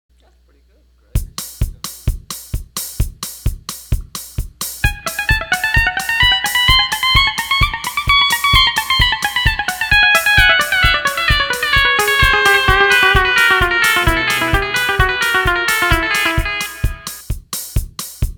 The echo delay is set for 346 millisecond delay.
Fast Echo Muted Tab 2.wma